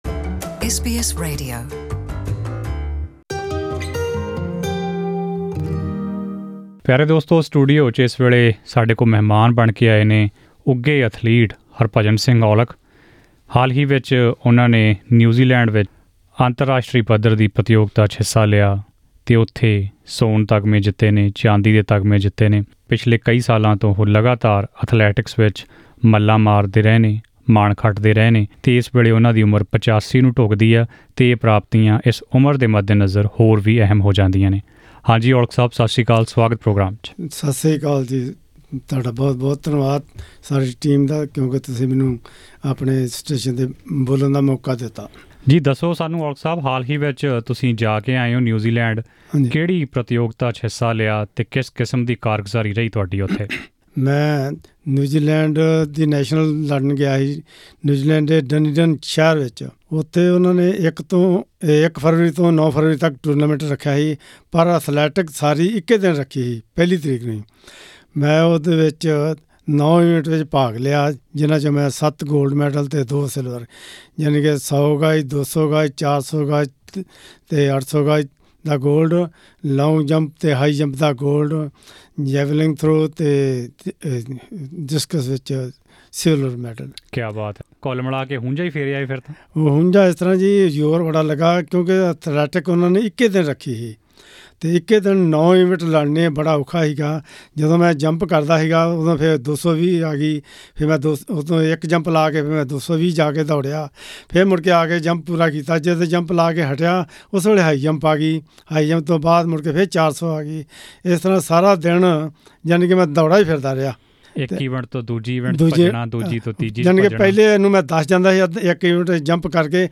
ਪੂਰੀ ਗੱਲਬਾਤ ਸੁਨਣ ਲਈ ਉੱਪਰ ਫੋਟੋ ‘ਤੇ ਦਿੱਤੇ ਆਡੀਓ ਲਿੰਕ ਉੱਤੇ ਕਲਿਕ ਕਰੋ....